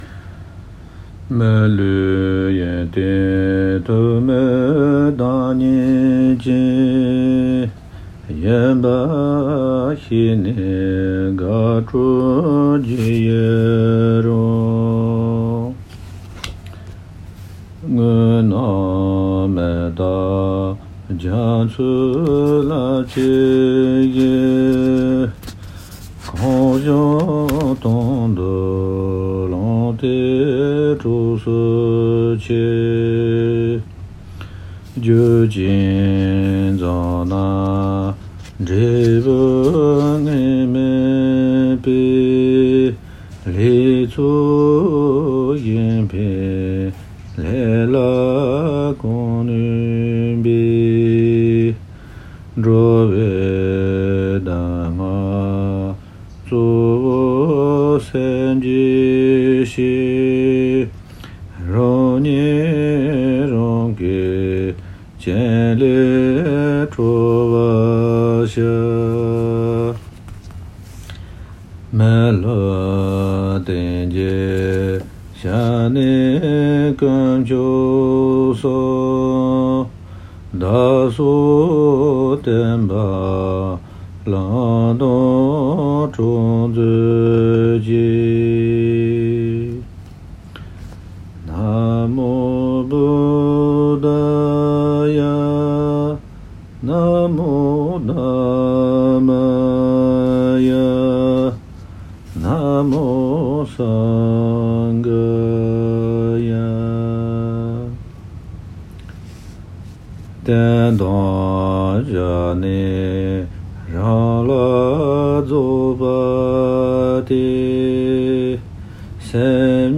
chanted
wp-content/uploads/2018/09/NectarOfThePath.mp3 Daily Prayer